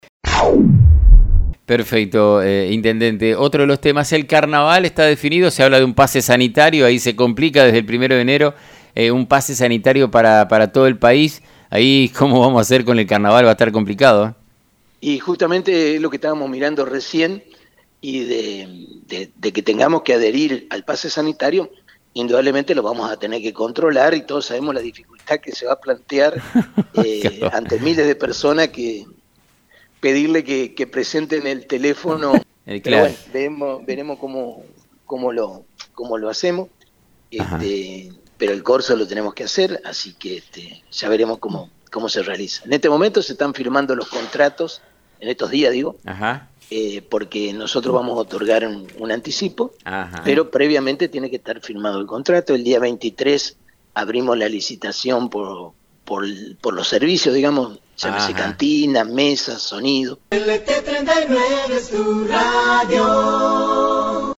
Presidente Municipal de Victoria – Domingo Maiocco